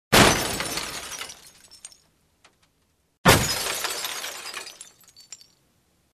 Звуки разбивания машины
На этой странице собрана коллекция реалистичных звуков разбивания автомобиля. Вы можете слушать и скачивать эффекты битья стекол, ударов по металлу кузова и пластику фар.